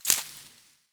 harvest_4.wav